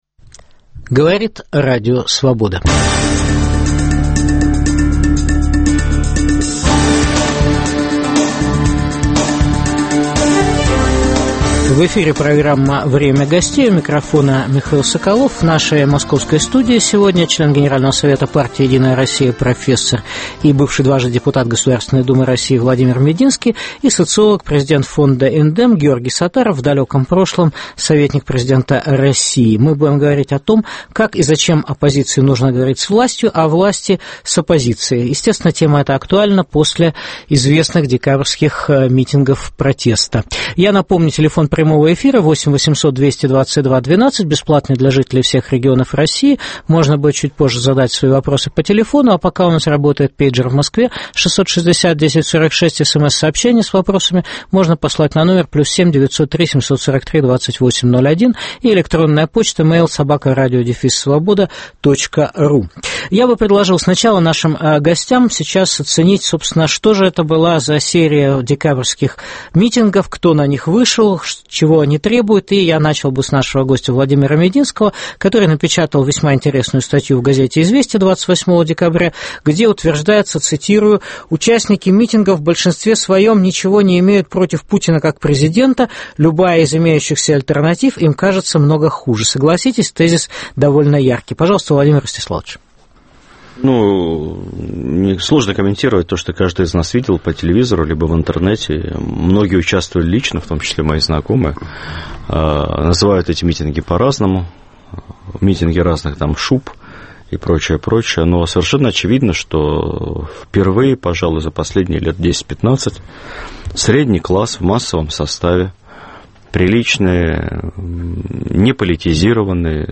Как и зачем оппозиции нужно говорить с властью, а власти - с оппозицией? В программе дискутируют член Генсовета партии "Единая Россия" Владимир Мединский и президент фонда Индем Георгий Сатаров.